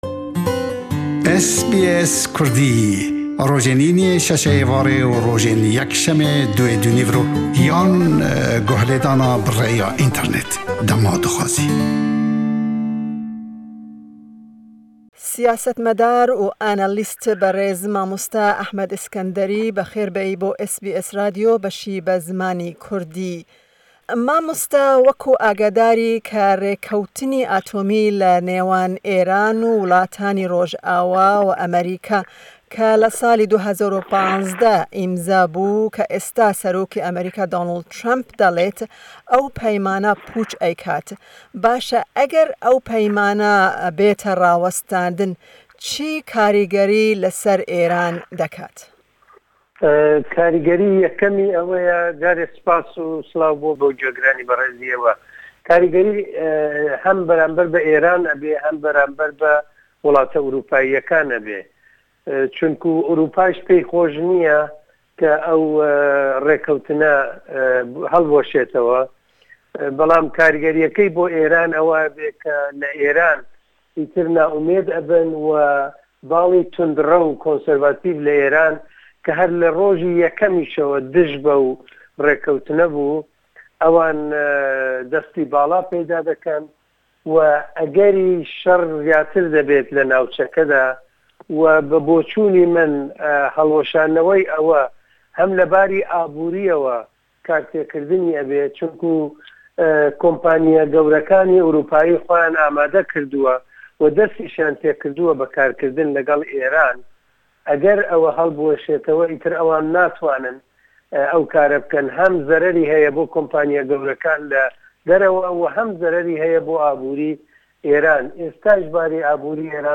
Em herweha li ser helweshandina peymana navokî ya di navberî DYA û Êranê de jî axifîn. Di hevpeyvînê de behs herweha li ser rewsha mafê mirovan û bi taybetî ya Kurdên rojhila taxifîn jî .